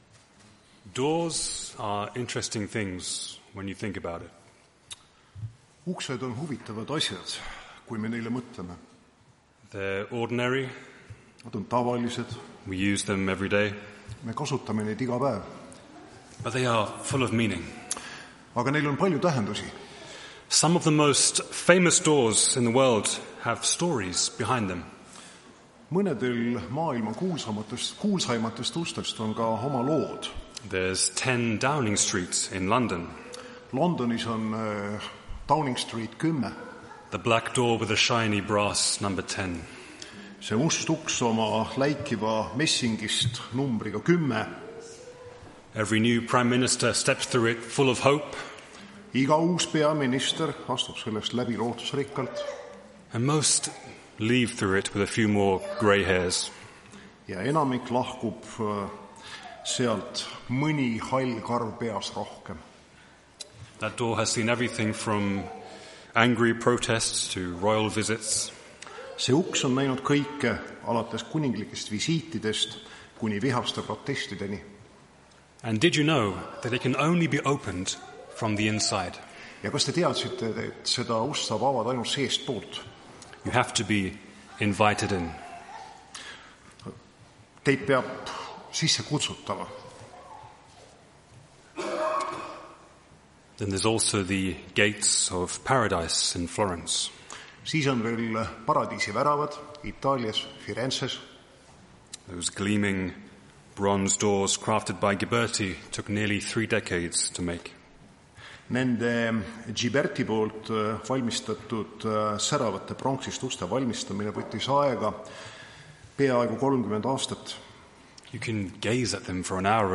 Tartu adventkoguduse 25.10.2025 teenistuse jutluse helisalvestis.